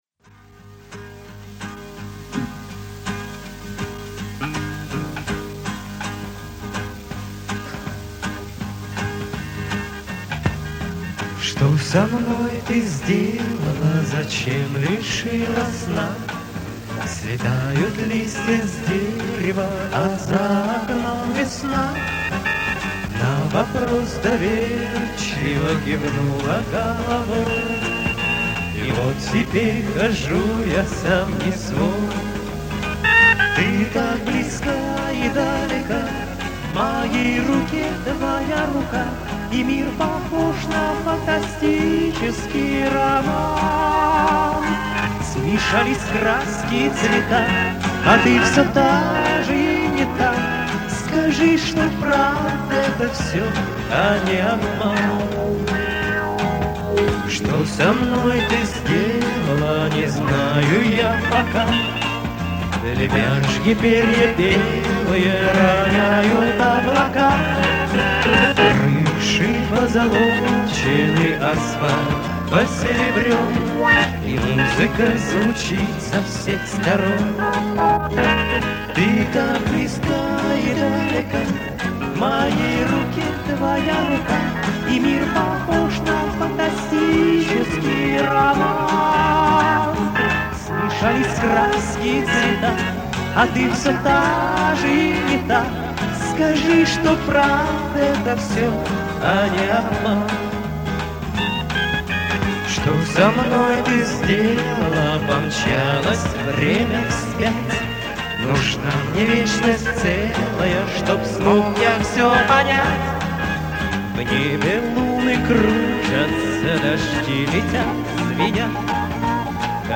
есть концерт.